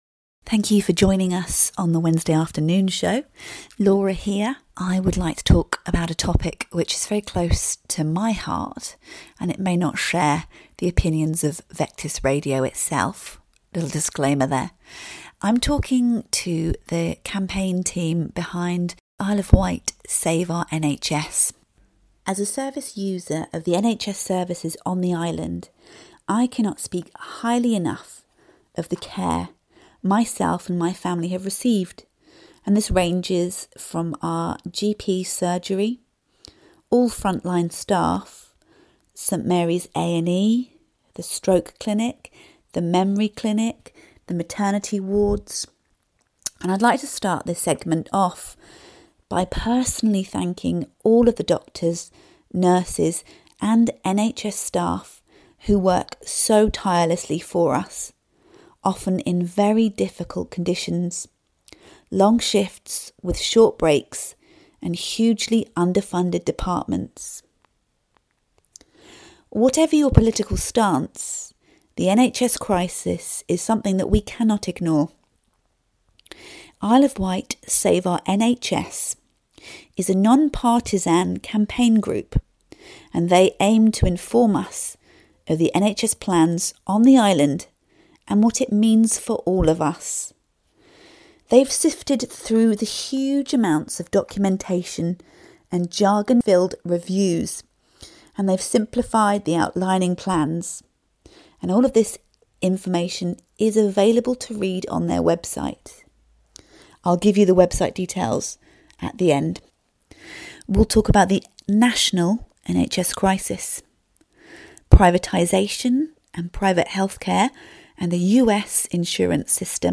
chats with the campaign group IW Save our NHS about the NHS crisis, privateers, privatisation and what the plans are for the island, and their impact on islanders.